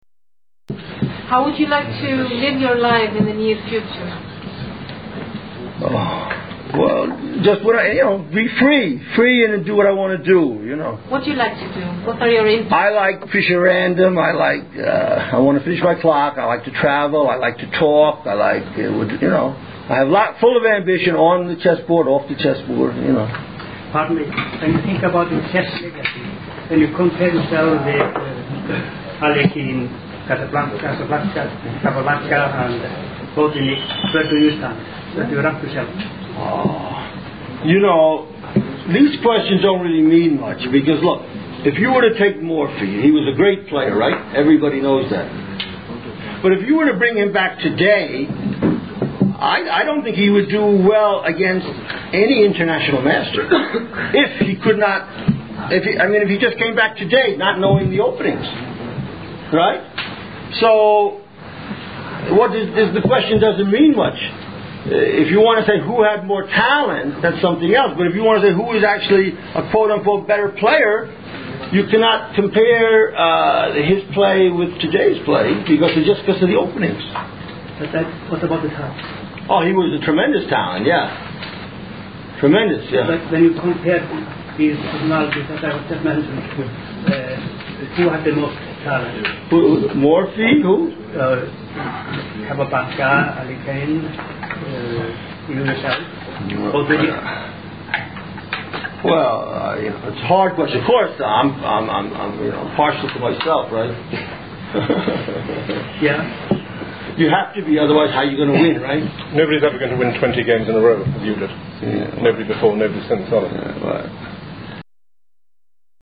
In this little interview snippet, from March 25, 2005, in Reykjavik, Iceland, Mr. Fischer mentions his hope to play in the future....